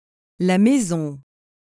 The pronunciation of maison is: may-sohn or [mɛzɔ̃]. The final ON is a nasal sound and the N is not pronounced.